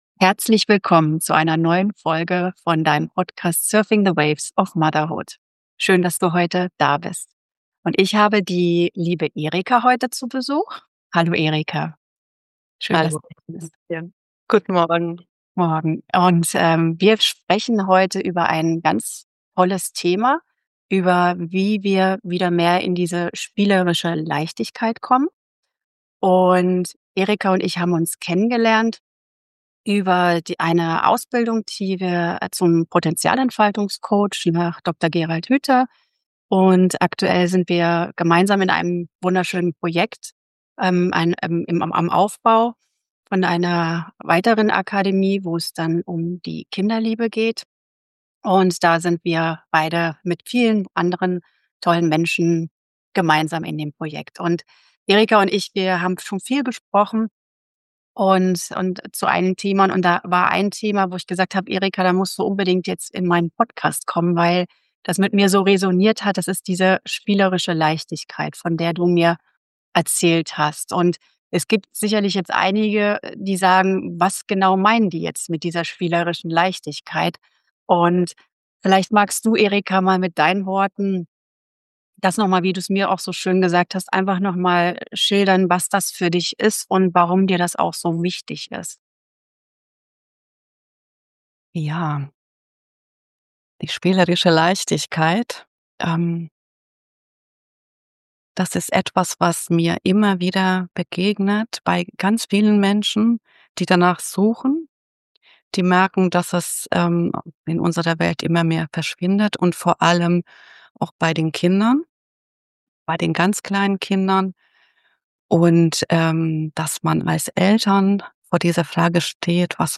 Dann ist dieses Gespräch genau das Richtige für dich.